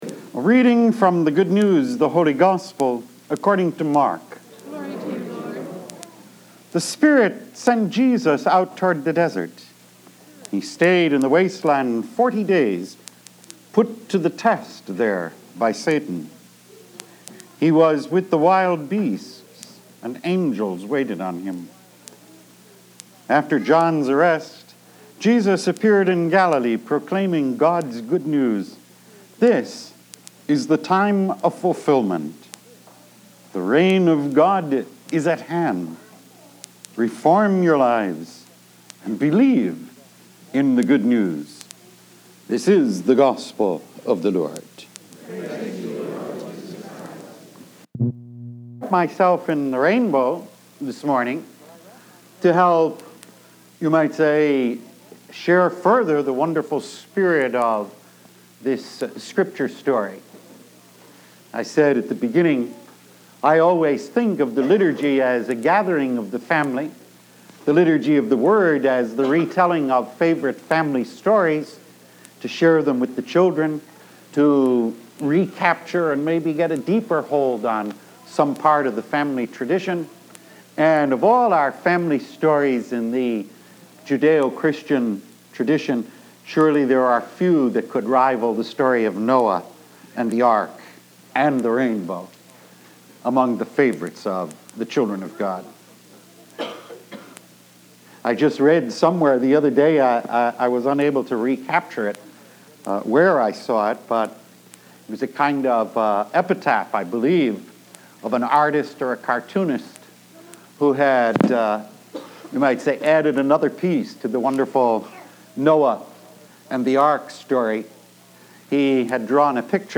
Diversity – Weekly Homilies
Originally delivered on February 20, 1994